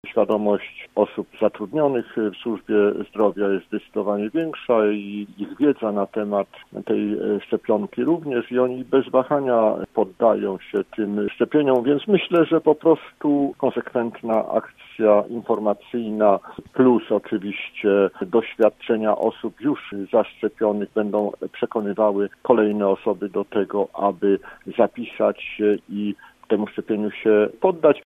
Prezes lubuskich struktur Prawa i Sprawiedliwości podkreślał w Rozmowie po 9, że w podjęciu decyzji może pomóc wiedza i akcja szczepień wśród personelu medycznego, szczepionego w pierwszej kolejności: